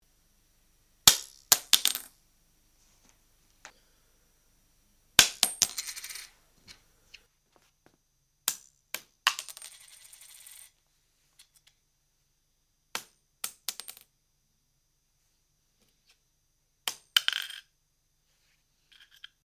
C'est donc une pièce de 1€ lâchée entre 50 cm et 1m du carrelage. Le micro est désolidarisé de la caméra, à 20 cm d'elle et à 5 cm du sol sur une pantoufle...
D'abord le micro sur Auto puis réglé manuellement.
La pièce qui tombe
Il y a une reverb un peu métallique sûrement due au contexte trop "carrelé..." mais à mon avis ce n'est pas le micro idéal pour ce genre de prise...
C'est vrai que c'est un peu trop aigu, et ça résonne.
Mais en effet, malgré cette (trop courte) distance, on entend encore un peu le discret bruit mécanique de la HV20...